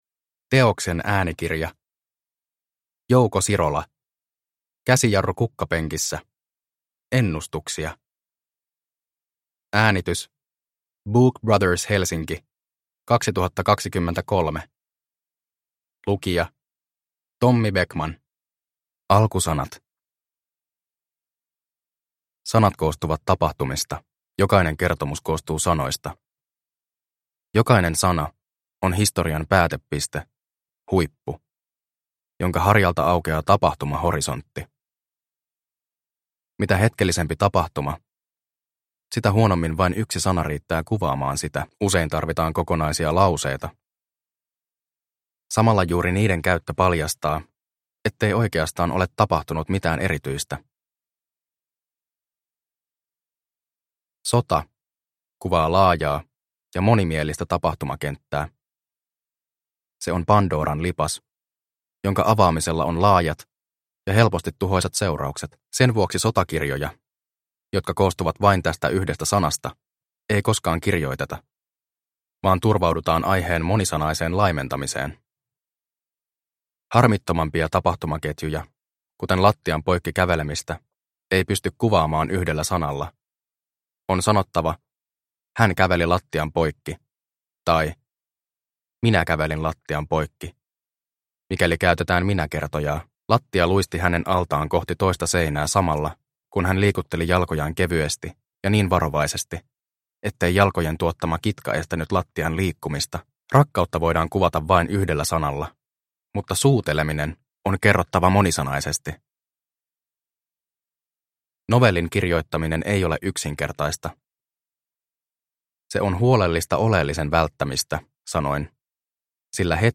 Käsijarru kukkapenkissä (ljudbok) av Jouko Sirola